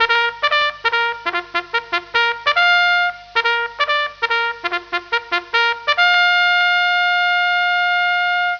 Trumpet2
Trumpet2.wav